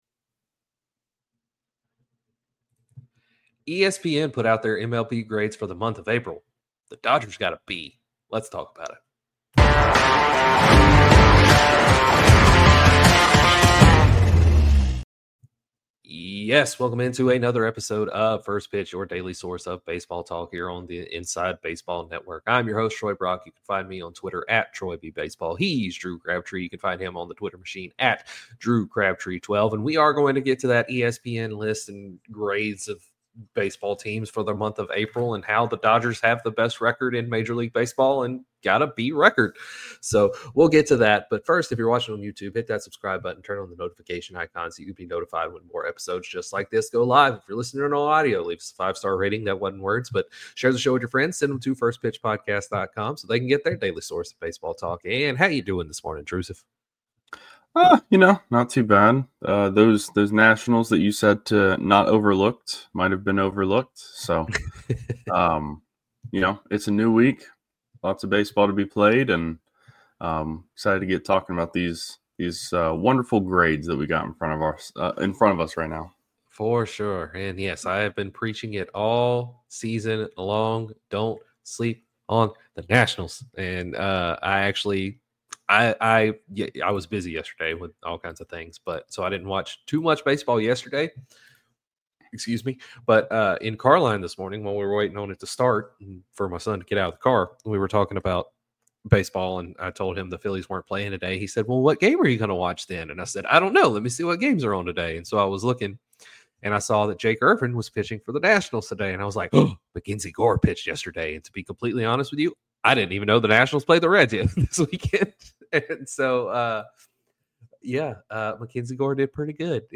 On today's show, the boys discuss yesterday's slate of games before diving into an ESPN article grading all 30 team's Aprils, with the Los Angeles Dodgers getting a "B." They then discuss today's slate of games and the strong week of games ahead.